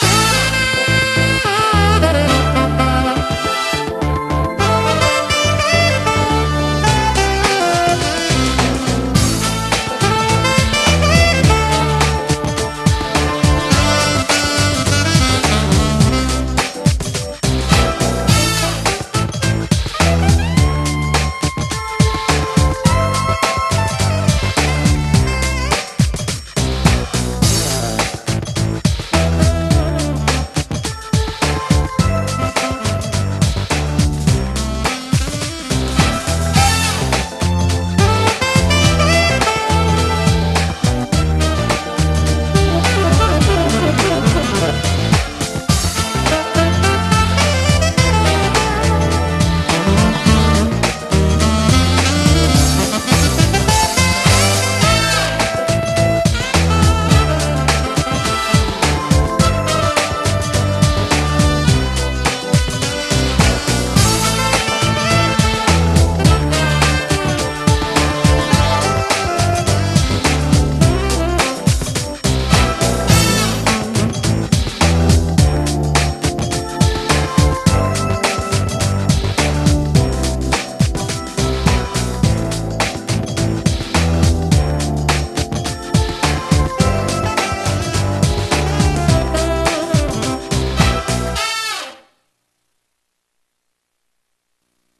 BPM105
Audio QualityLine Out